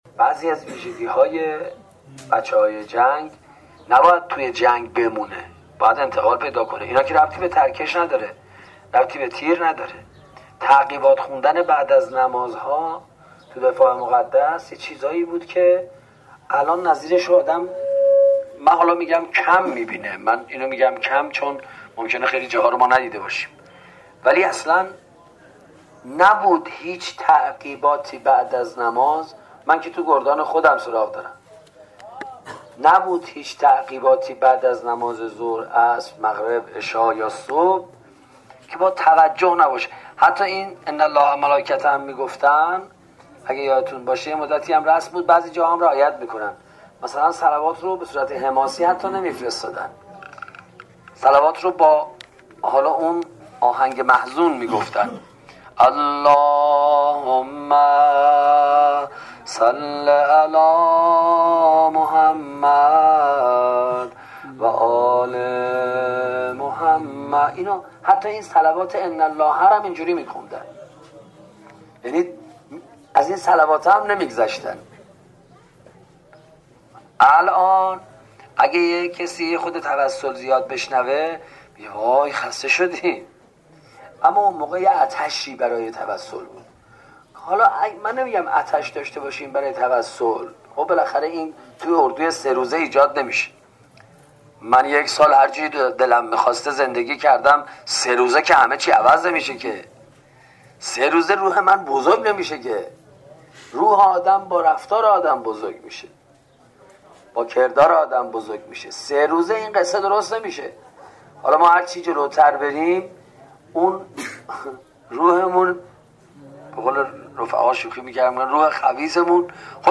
روایتگری استاد پناهیان در مناطق عملیاتی جنوب — پایگاه دانلود مذهبی و فرهنگی زهرامدیا